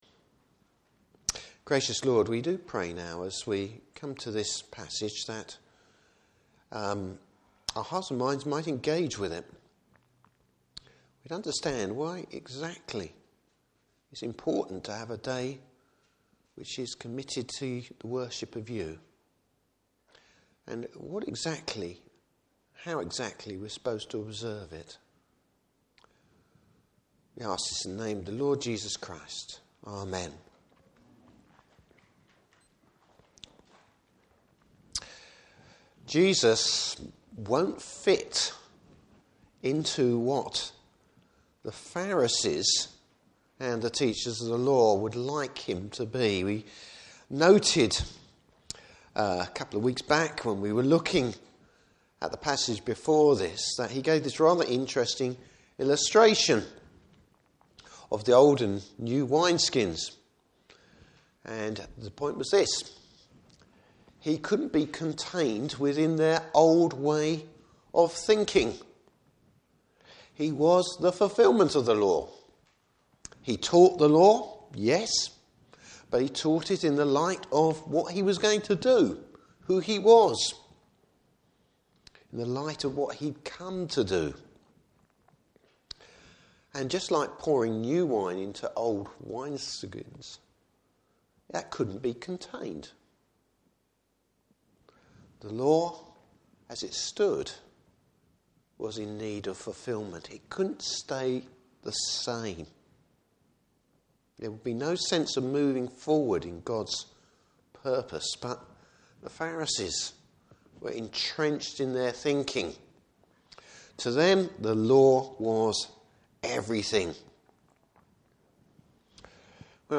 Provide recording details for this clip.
Service Type: Morning Service Bible Text: Luke 6:1-11.